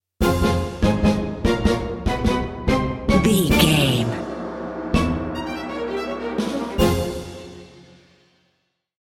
Ionian/Major
Fast
orchestra
violin
strings
brass section
cello
piccolo
flute
drums
tense
suspenseful
driving
aggressive
dramatic
energetic
percussion